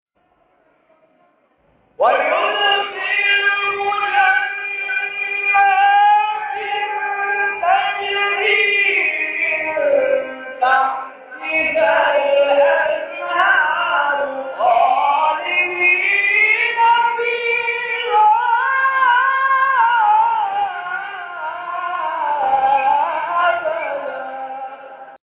گروه شبکه اجتماعی: مقاطعی صوتی از تلاوت قاریان ممتاز کشور ارائه می‌شود.